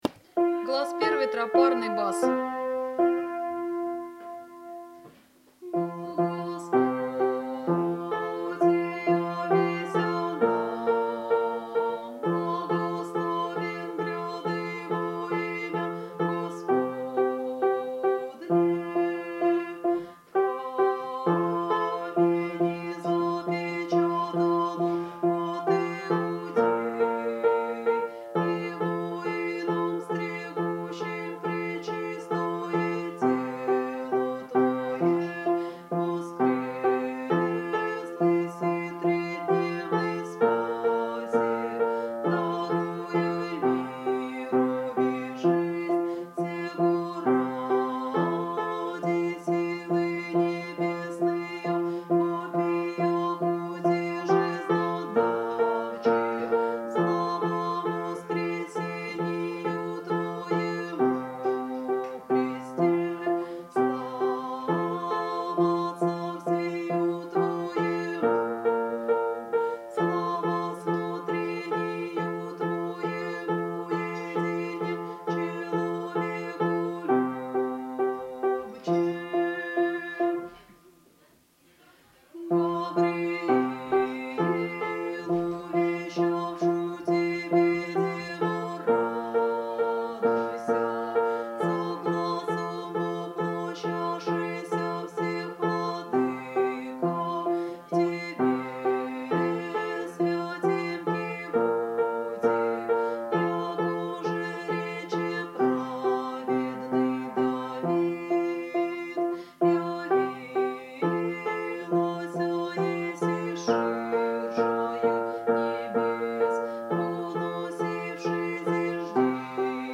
1troparnuy_bas.MP3